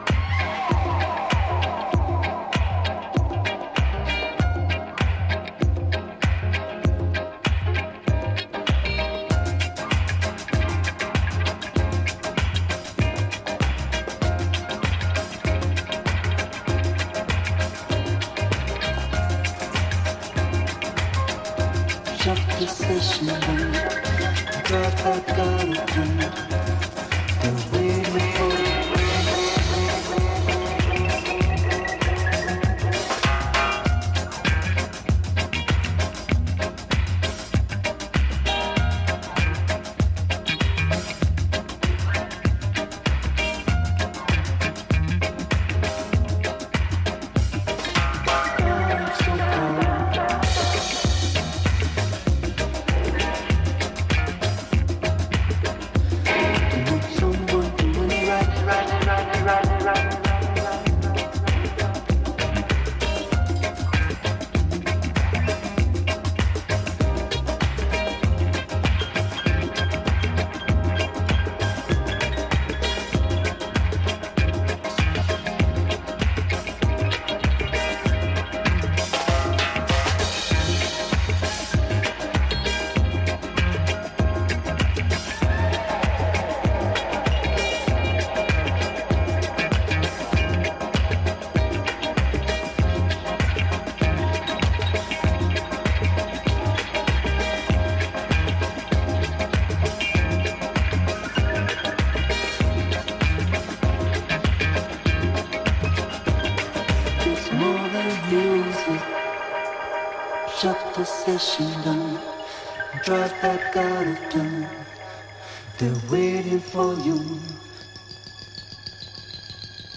レゲエ〜ダブ・サウンドを軸にブギー・ディスコやアフロ・サウンド等、グルーヴィーで心地良いナンバーがギッシリ！